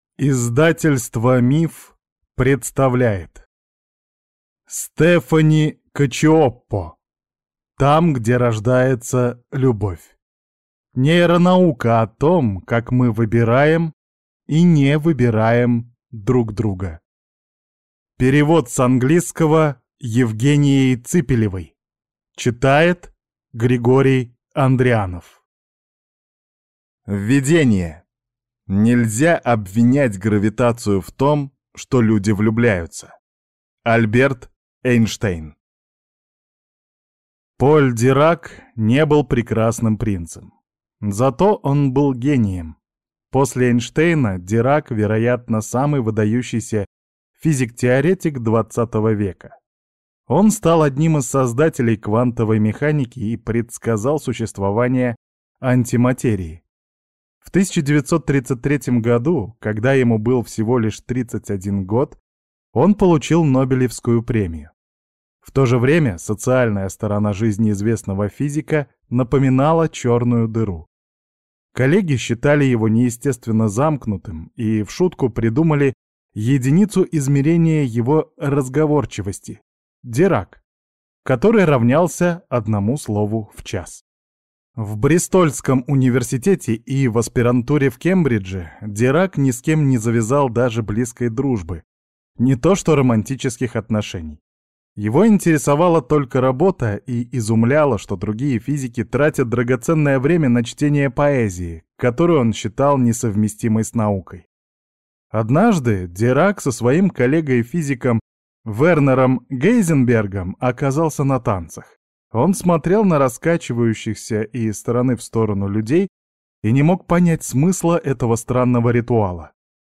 Аудиокнига Там, где рождается любовь. Нейронаука о том, как мы выбираем и не выбираем друг друга | Библиотека аудиокниг